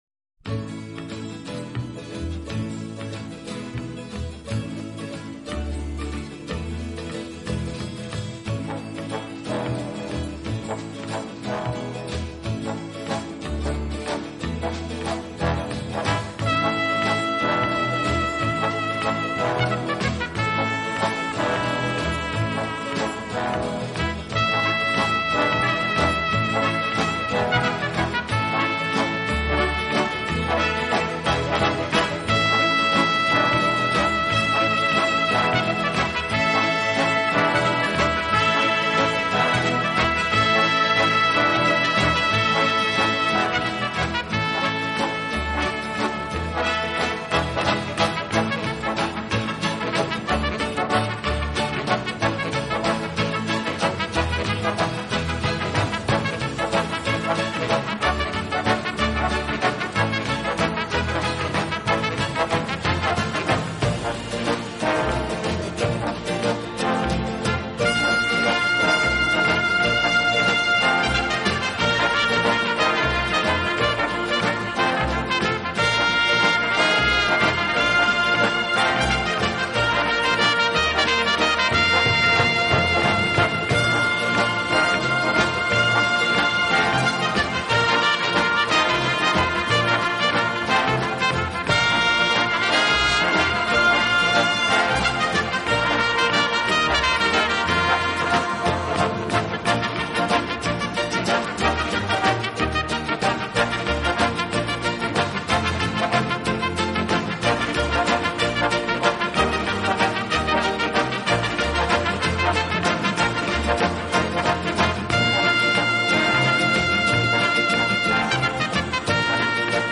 温情、柔软、浪漫是他的特色，也是他与德国众艺术家不同的地方。